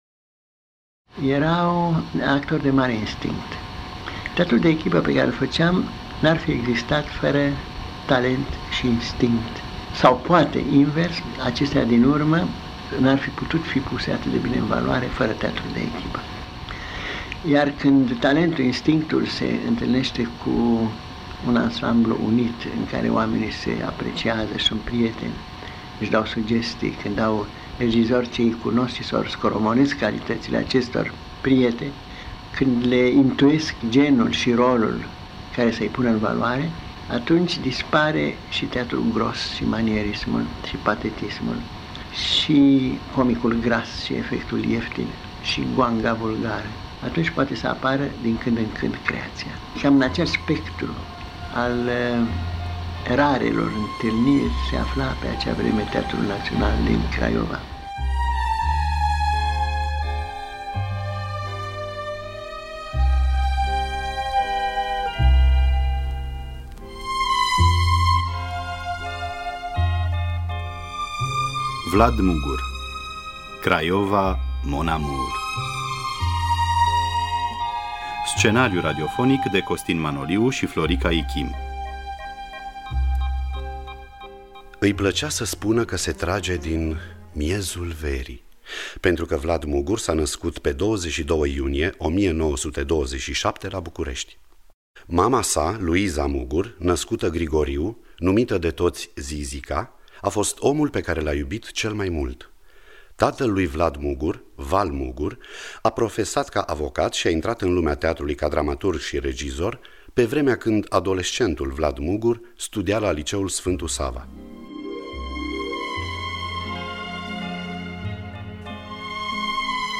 Scenariu radiofonic de Ion-Costin Manoliu și Florica Ichim.
Spectacol de teatru-document bazat pe confesiunile regizorului Vlad Mugur. Cu participarea extraordinară a actorilor Olga Tudorache, Victor Rebengiuc, Dumitru Rucăreanu și Constantin Codrescu.